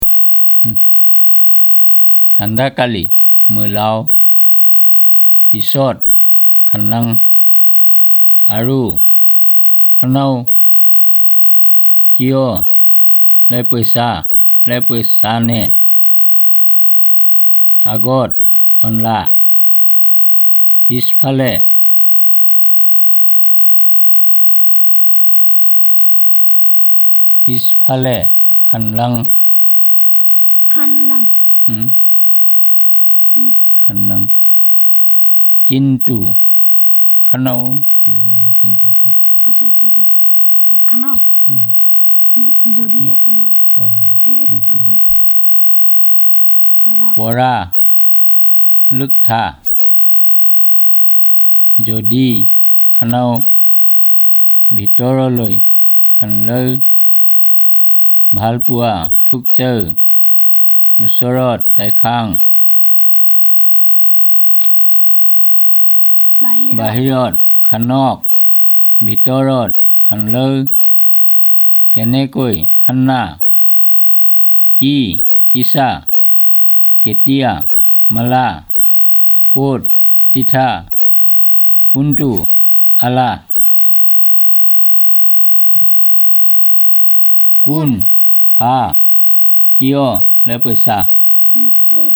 NotesThis is an elicitation of words about directions and interrogative pronouns using a questionnaire.